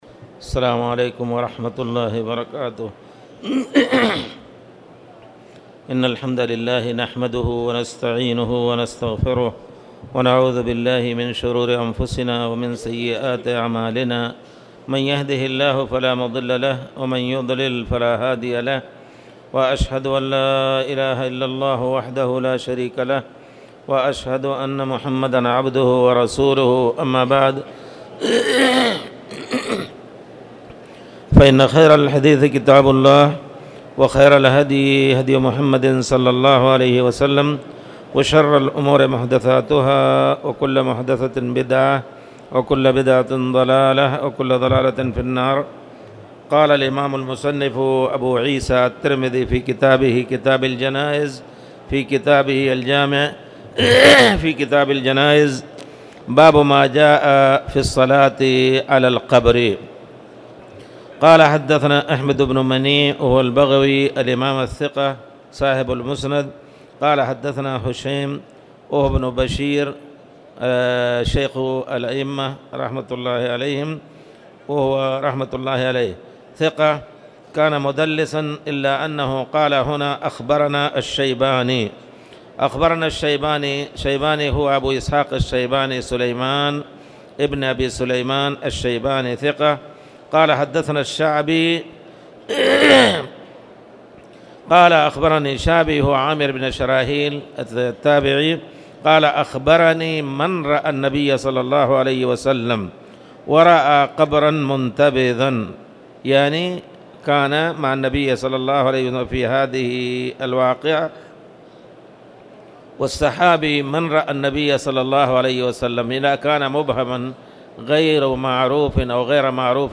تاريخ النشر ٨ شوال ١٤٣٧ هـ المكان: المسجد الحرام الشيخ